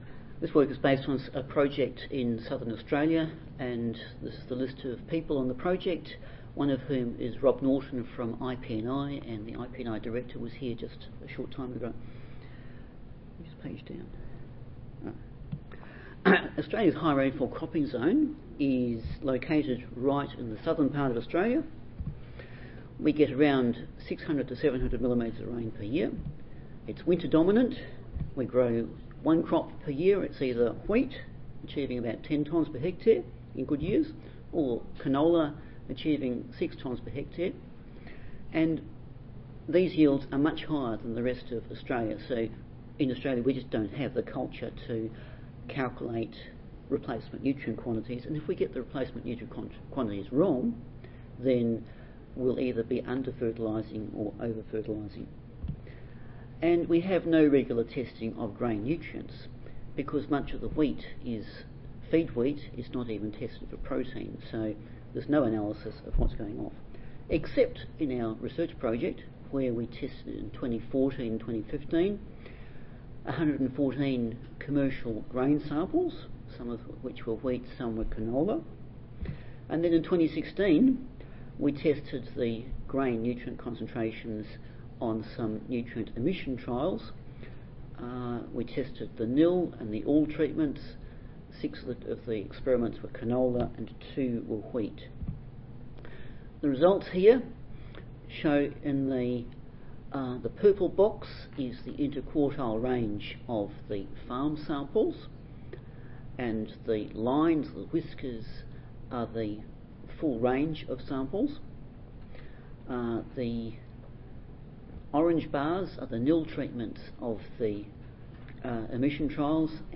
2017 Annual Meeting | Oct. 22-25 | Tampa, FL
Audio File Recorded Presentation